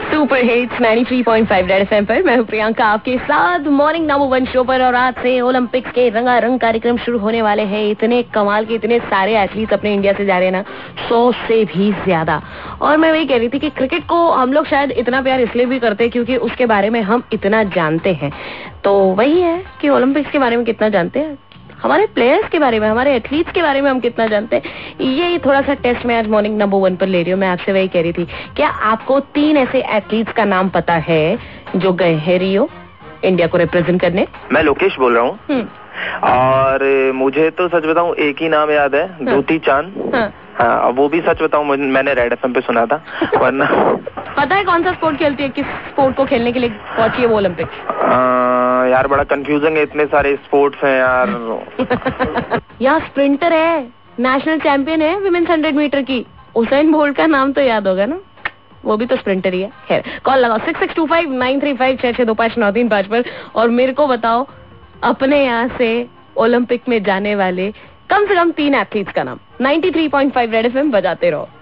WITH CALLER